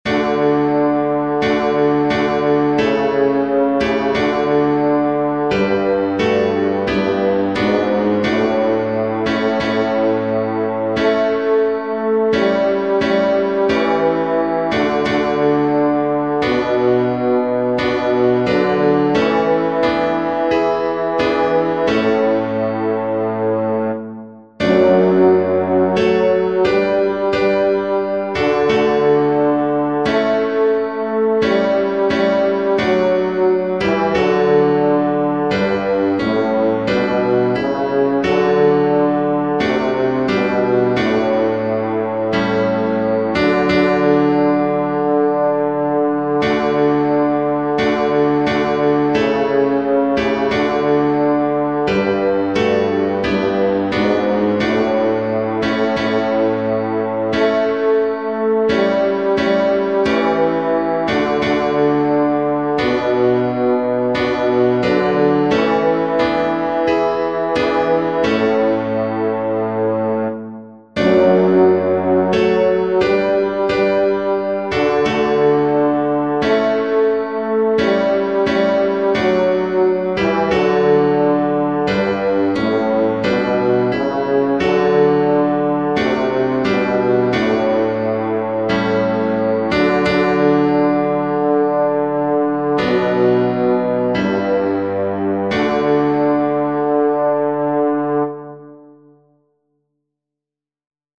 panis_angelicus_pd-bass.mp3